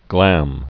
(glăm)